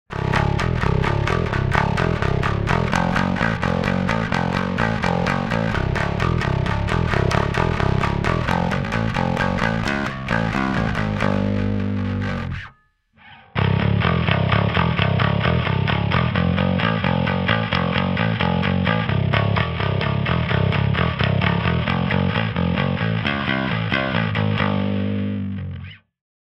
Ich habe mal richtig schnell und dreckig etwas aufgenommen. Spector Euro5 --> Stomp --> Cubase